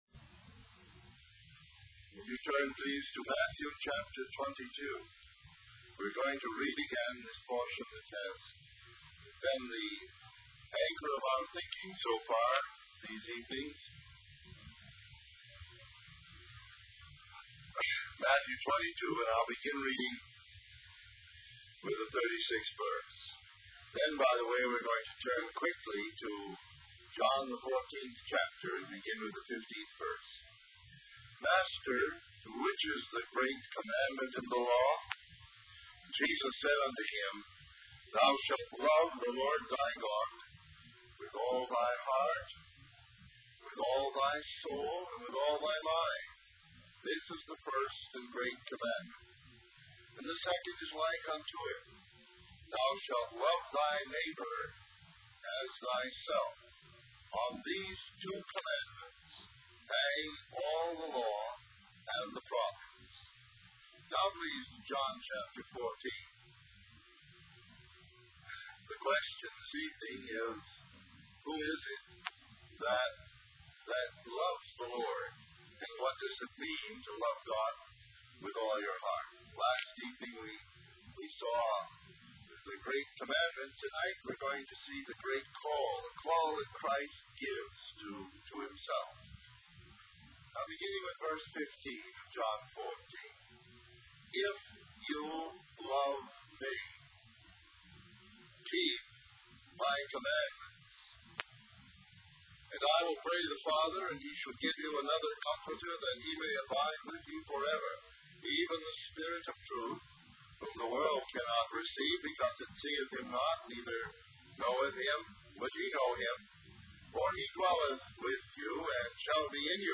In this sermon, the preacher tells a story about a young man who committed his life to Christ despite his mother and grandmother's disapproval.